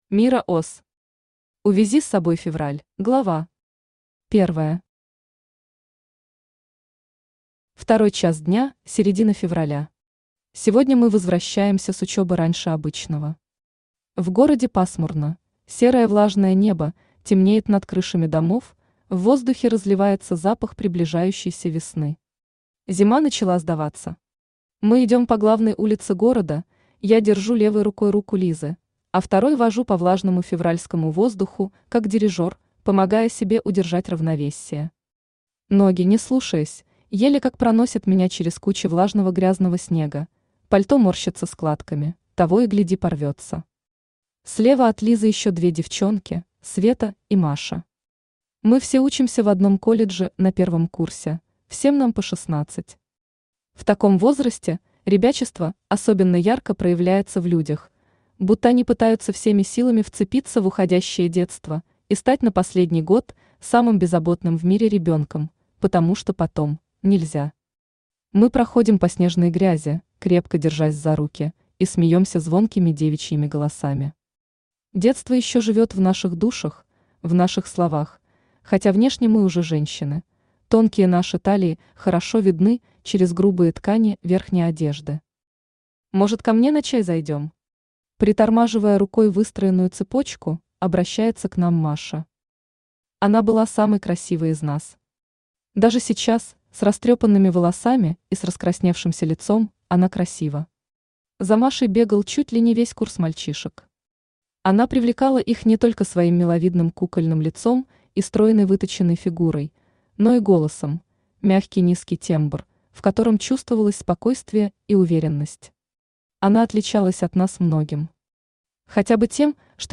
Аудиокнига Увези с собой февраль | Библиотека аудиокниг
Aудиокнига Увези с собой февраль Автор Мира Оз Читает аудиокнигу Авточтец ЛитРес.